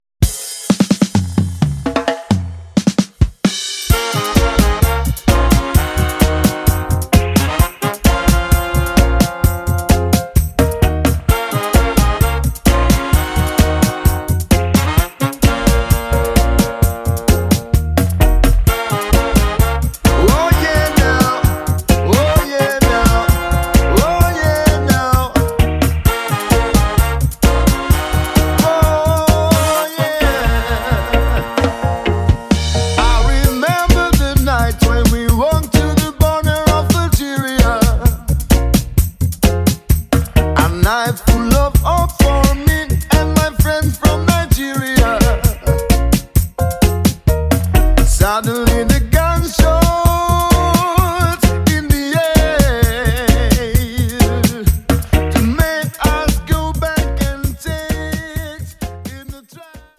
Genre: Reggae, Roots, Dub